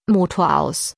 im Anhang die App PreMotOff, welche bei Schalter SD hinten jede 10s die Ansage Motoraus bringt.
Bist Du sicher die Voice Datei Motoraus.wav in den Audio Ordner auf obere Ebene kopiert zu haben?